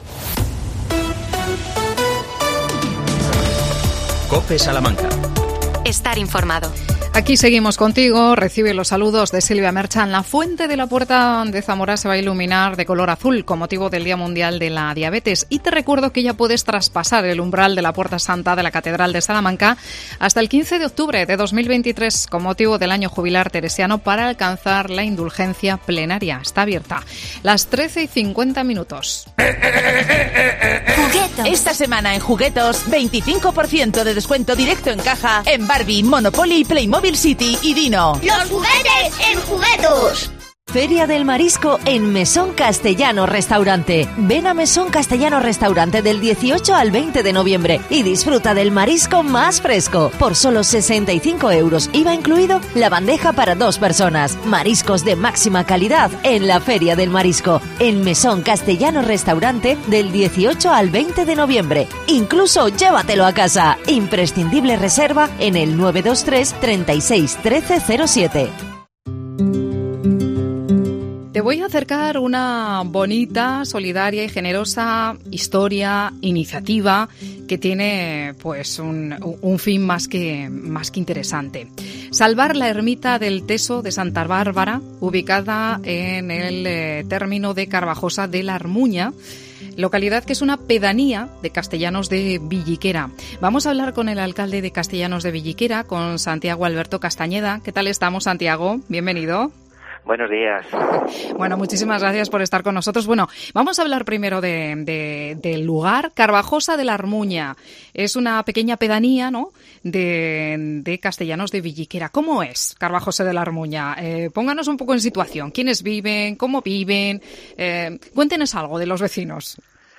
Salvemos la Ermita de Carbajosa de Armuña. Entrevistamos al alcalde de Castellanos de Villiquera, Santiago Alberto Castañeda.